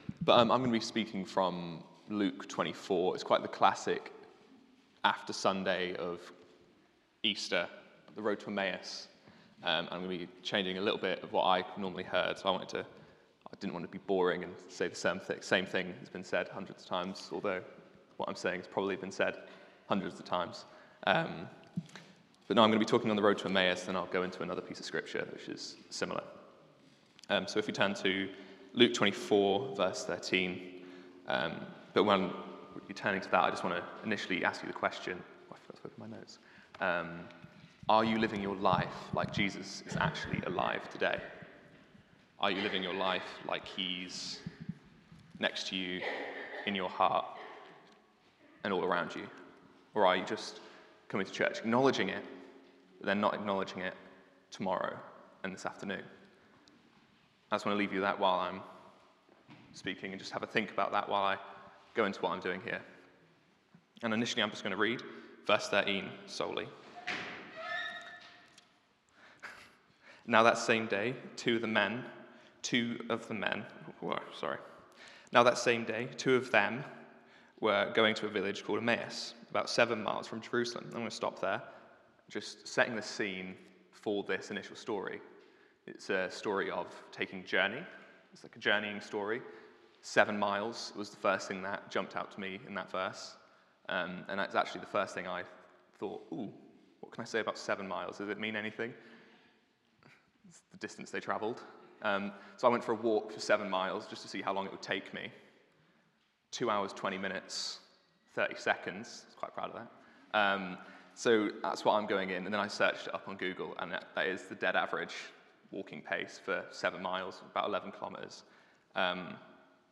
Sermon - Two Journeys